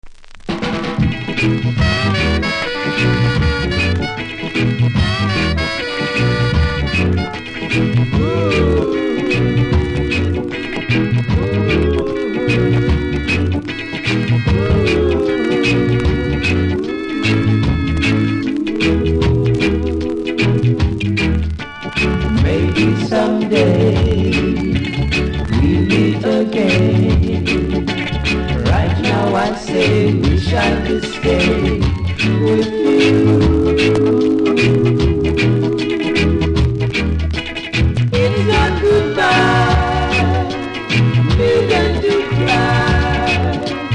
両面使えるロック・ステディ♪